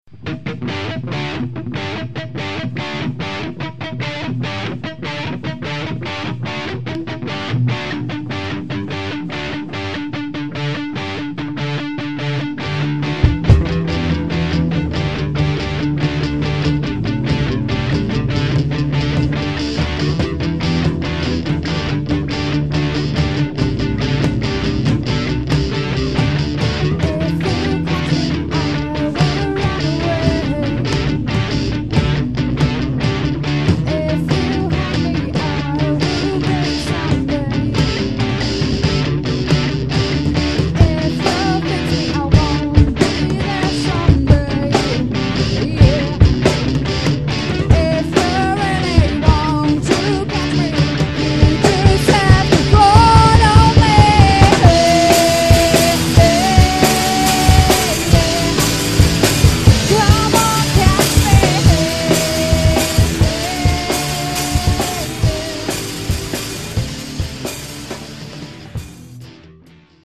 Vocals
Drums
Guitar
Bass